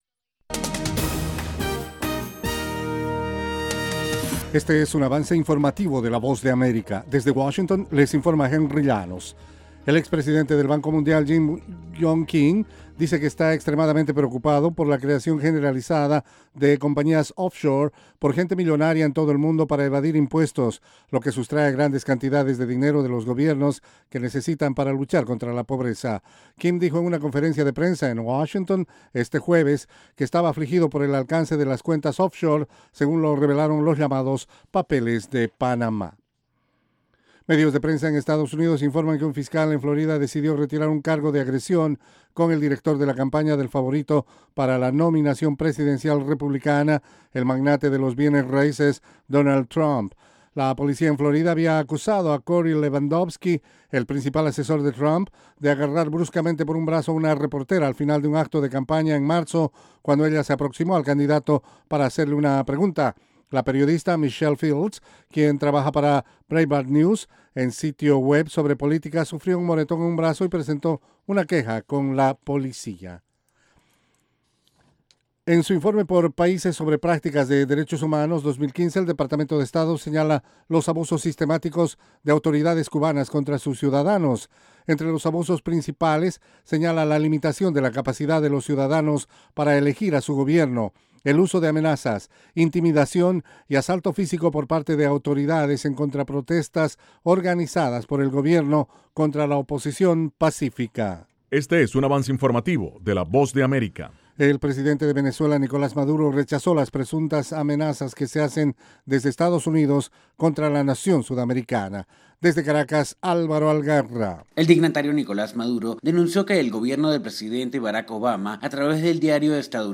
Capsula informativa de 5 minutos con el acontecer noticioso de Estados Unidos y el mundo.